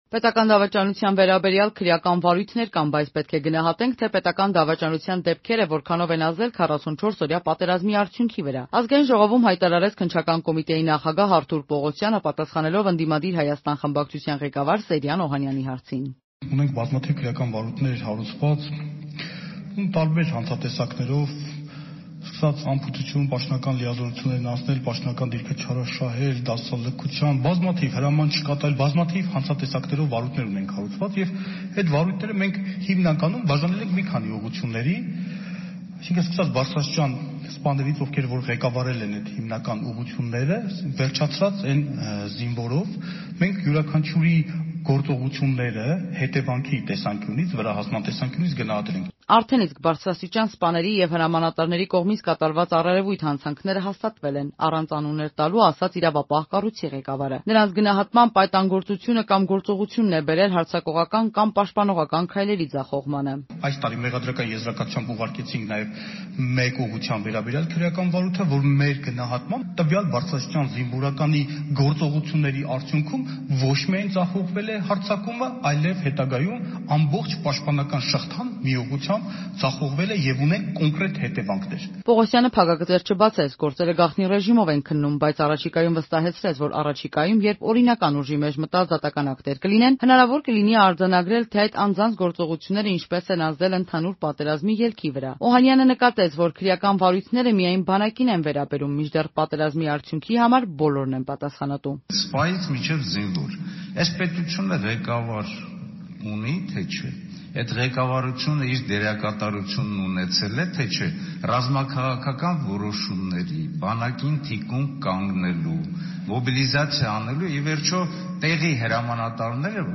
Առավել մանրամասն՝ «Ազատության» ռեպորտաժում.